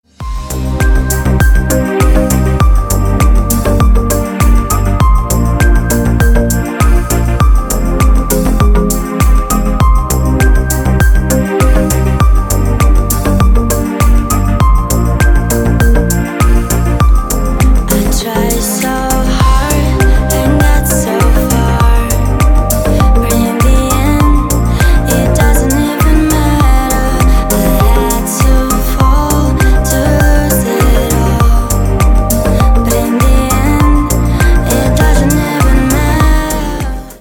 Красивая музыка в рингтонах
• Качество: Хорошее
• Песня: Рингтон, нарезка